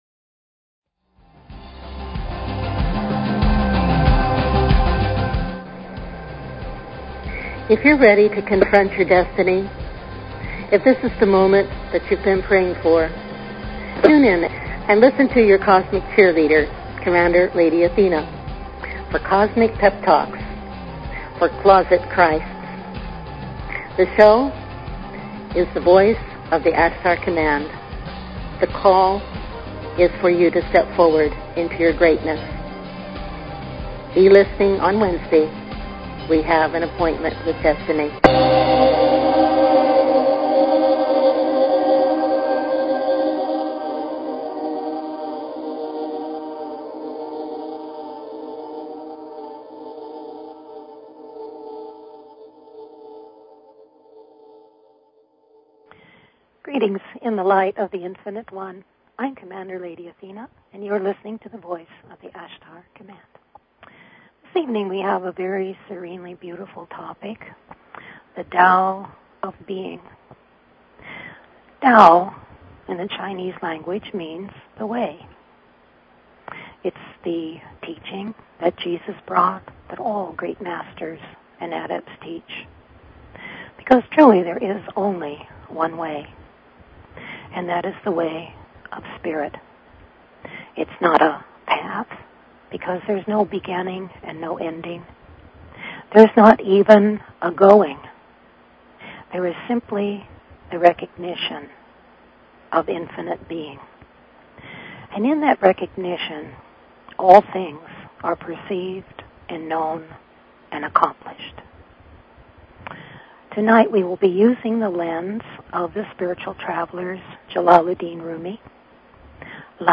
Courtesy of BBS Radio
THE TAO OF BEING: We examine the Truth of our Beingness through the lens of the Tao, the Way as taught by Jesus, Jalaludin Rumi, Lao Tsu & my teacher the Traveler, John-Roger. Serenely lovely this meditative show will center you once again in your own inner Sanctuary of all pervasive peace.